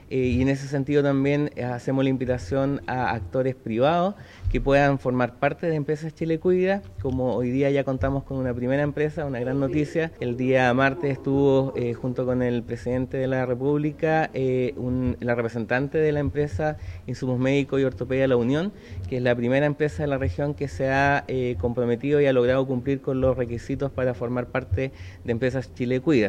El seremi de Desarrollo Social en Los Ríos, Roberto Giubergia, señaló que una empresa de La Unión formará parte de la entrega de atención preferencial.